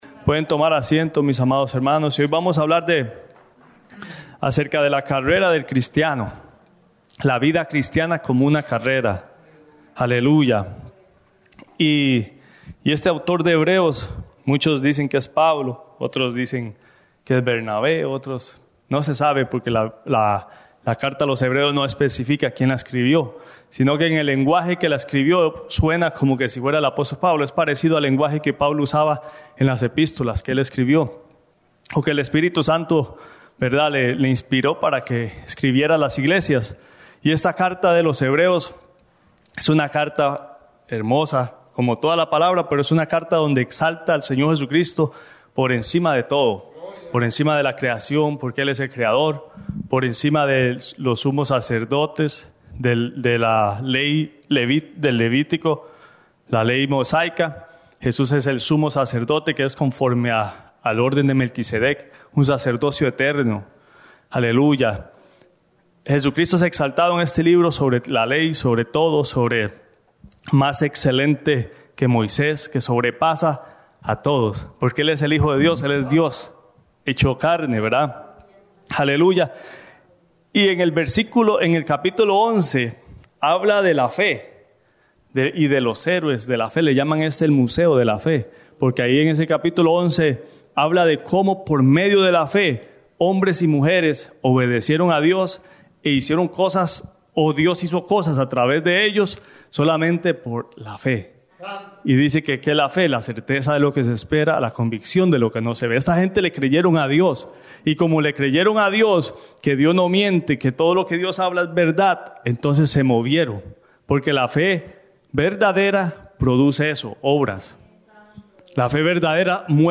La Vida Cristiana Como Una Carrera Predica